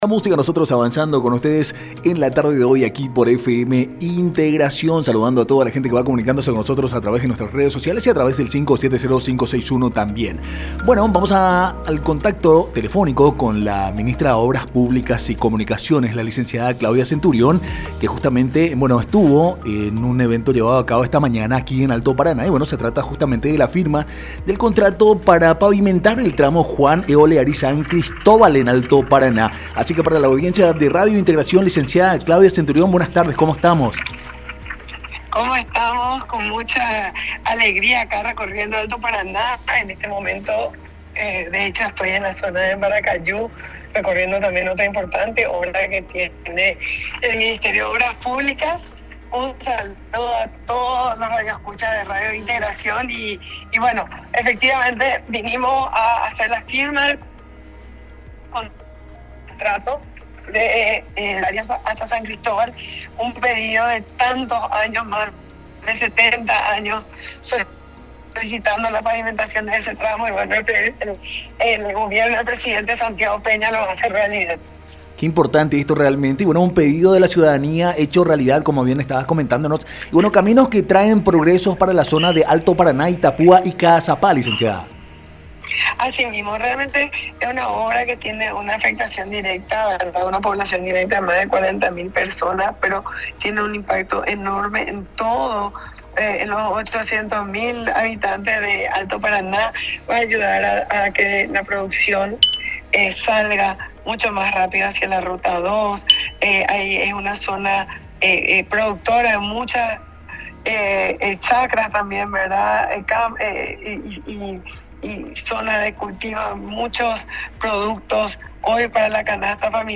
Entrevista Ing. Claudia Centurión
ENTREVISTA-MOPC.mp3